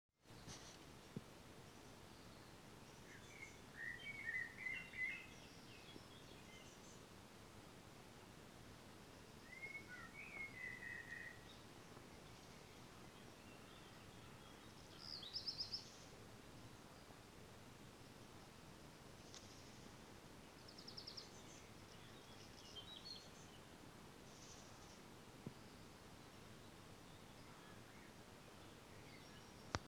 Dinge die ich im Garten viel zu selten höre